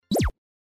Звук выключения для видеомонтажа